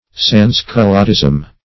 Search Result for " sans-culottism" : The Collaborative International Dictionary of English v.0.48: Sans-culottism \Sans`-cu*lot"tism\, n. [F. sans-culottisme.] Extreme republican principles; the principles or practice of the sans-culottes.